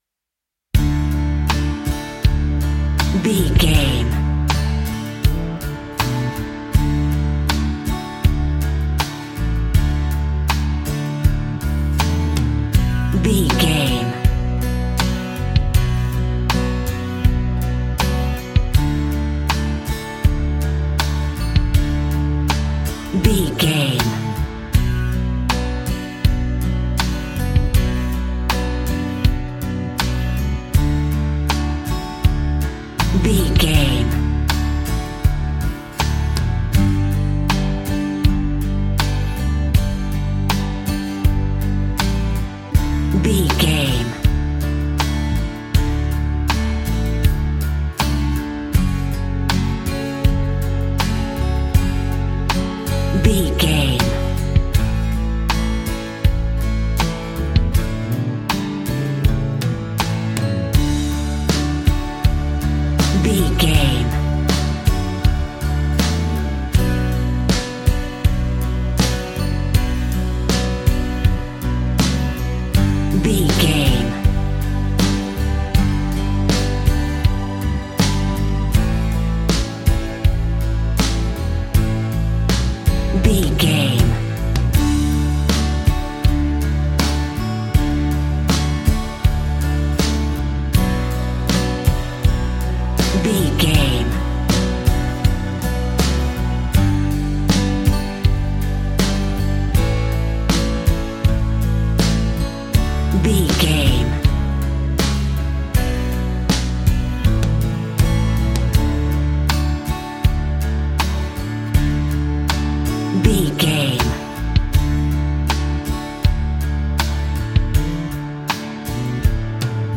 Ionian/Major
D
romantic
acoustic guitar
bass guitar
drums